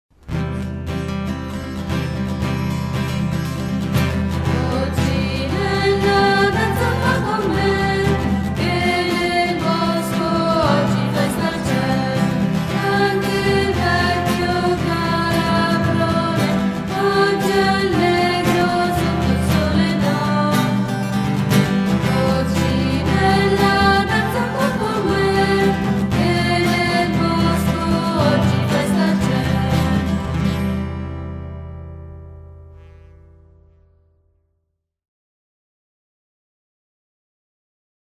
Canto del cerchio DO SOL LA- SOL Coccinella, danza un po� con me FA DO SOL DO che nel bosco oggi festa c�� FA DO SOL Anche il vecchio calabrone DO FA DO SOL oggi � allegro sotto il cielo d�or.